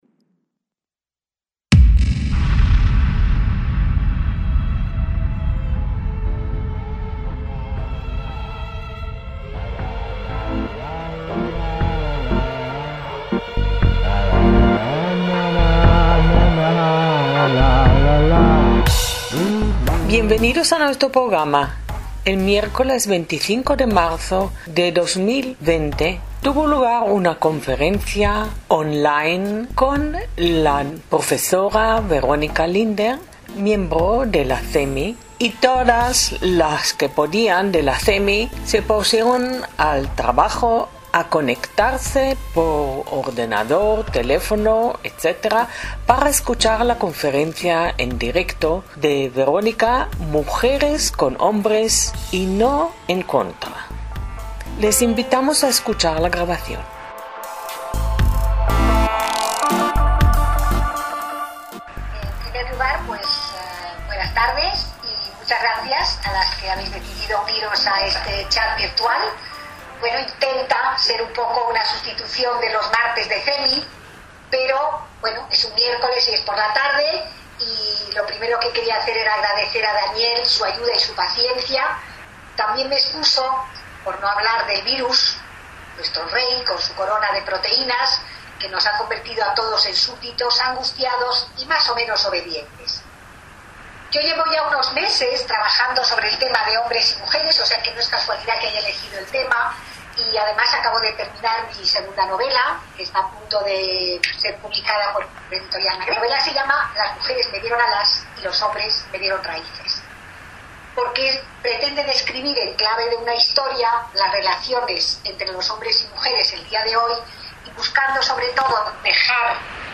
ACTOS EN DIRECTO - Generalmente, esta sección trae los audios de actos, presentaciones y charlas que se llevaron a cabo originalmente delante de público, pero las actuales circunstancias han obligado a una nueva vuelta de tuerca tecnológica, con la emisión a través de plataformas tecnológicas.